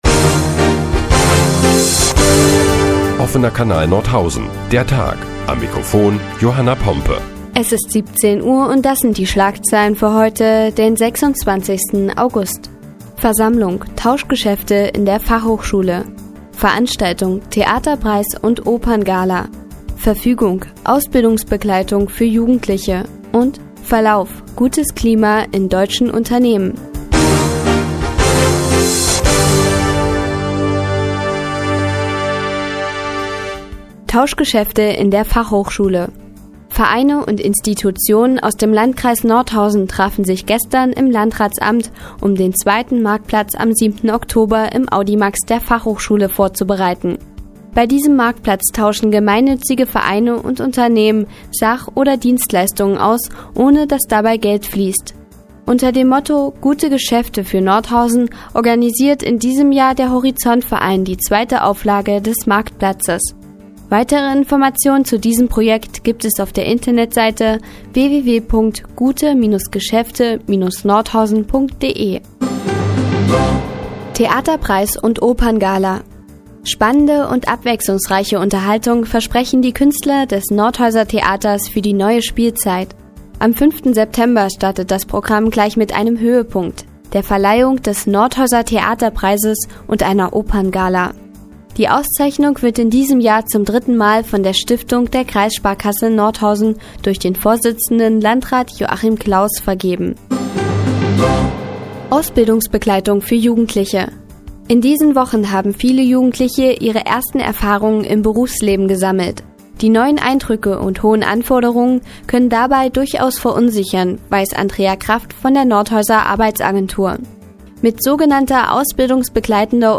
Die tägliche Nachrichtensendung des OKN ist nun auch in der nnz zu hören. Heute geht es unter anderem um Tauschgeschäfte in der Fachhochschule und Ausbildungsbegleitung für Jugendliche.